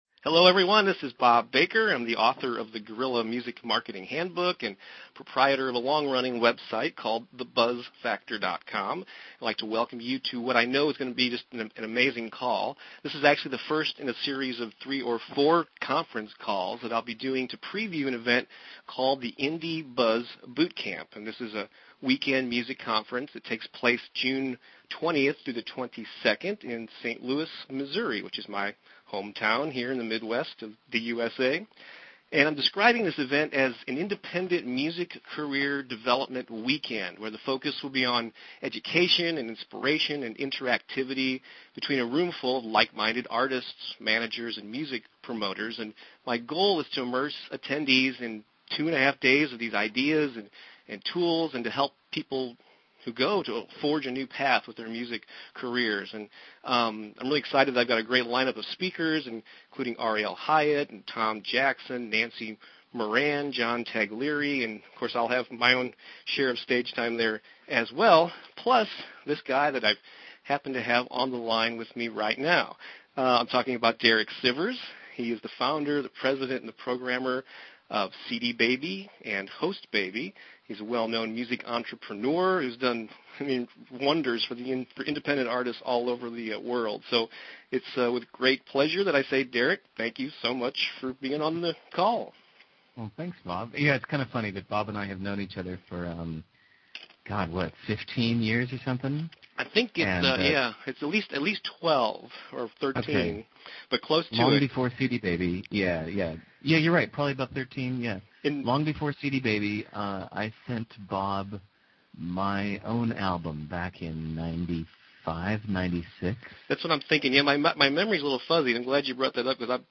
Derek Sivers of CD Baby: Audio Interview
The conversation lasted a full hour and 25 minutes, and it was a good one.
This is the first in a series of free conference calls that preview the Indie Buzz Bootcamp music conference, which takes place June 20-22 in St. Louis, MO.